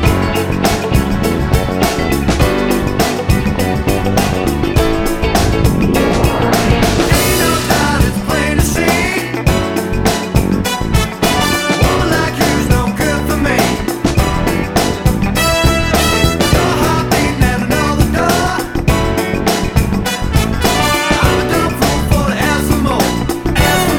Duet Version Pop (1990s) 4:03 Buy £1.50